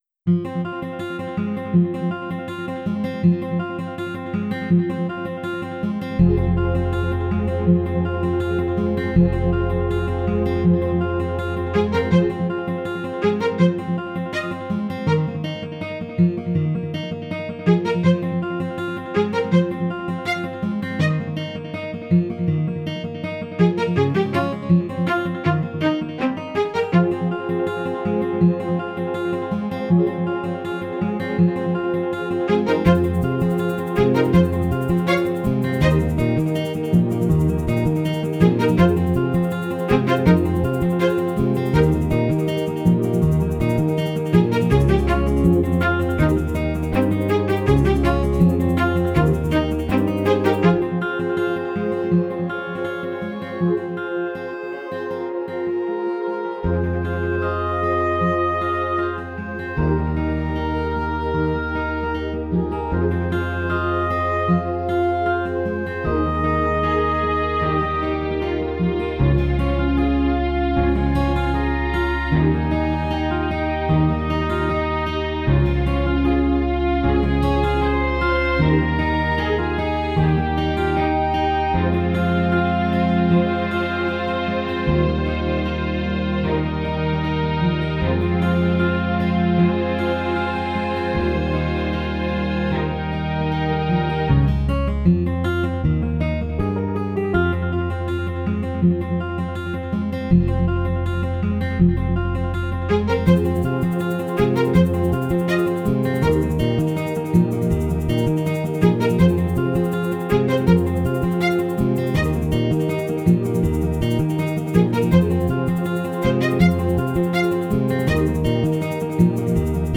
Tags: Piano, Clarinet, Woodwinds, Strings, Guitar, Percussion, Digital
Guitar, English horn, strings